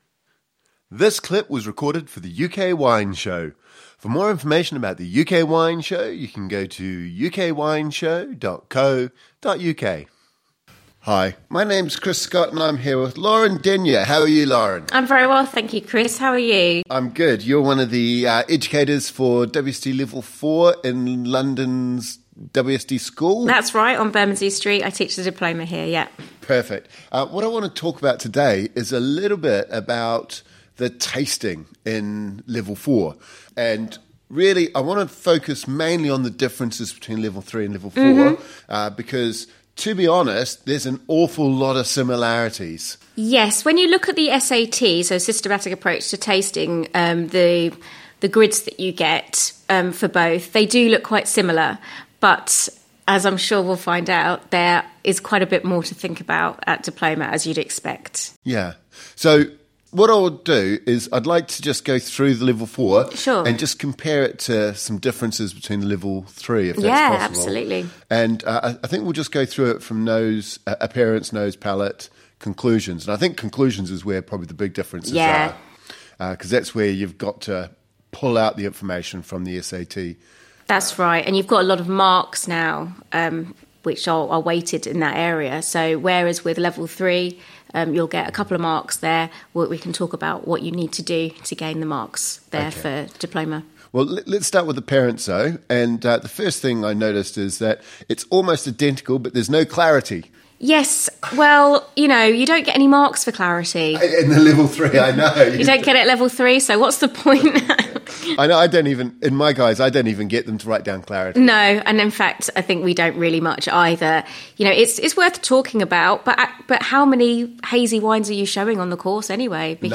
Overview In this second interview we focus on the differences between the SAT for Level 3 and Level 4 covering Appearance, Nose, Palate and Conclusions.